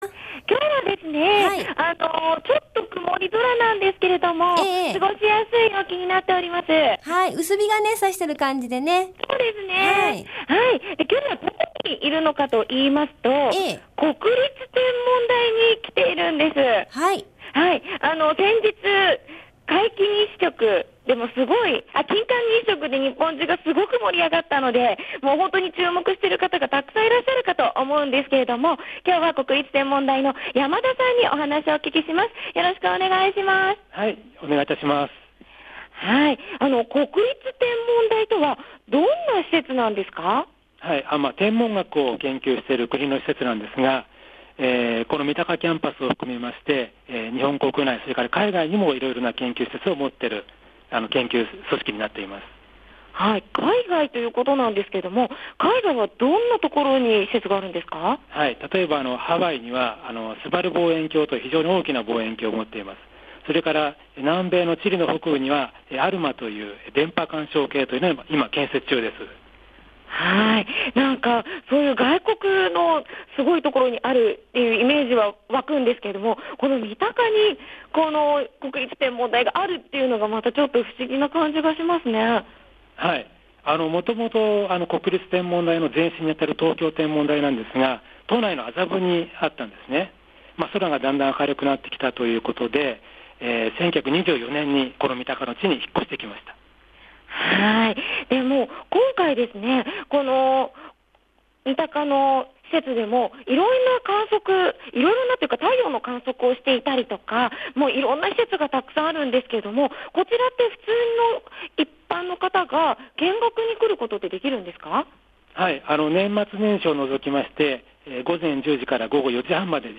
午後のカフェテラス　街かどレポート
今日は国立天文台に始めて！！行ってきましたー（＾－＾）